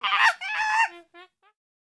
sound / monster2 / monkey / dead_1.wav
dead_1.wav